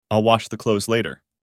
Portanto, a pronúncia será com som de /z/, ou seja, /klouz/.